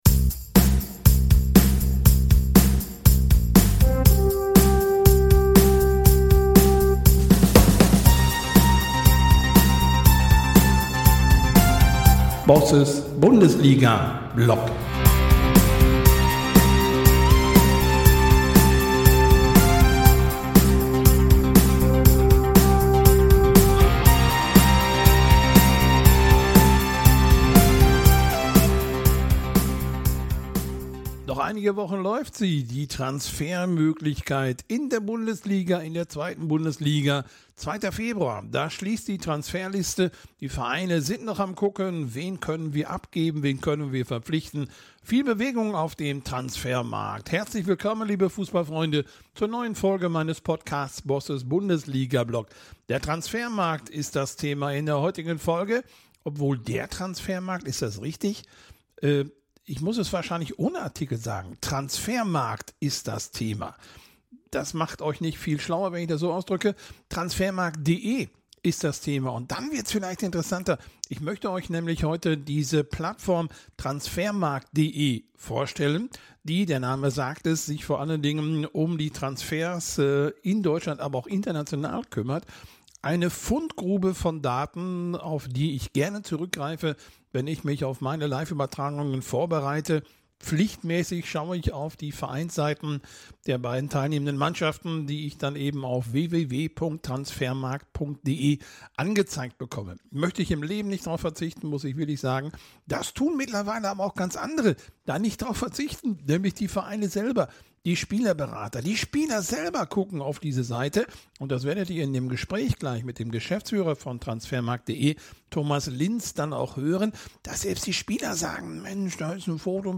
Ein Gespräch über Zahlen, Macht, Leidenschaft und die Zukunft des ...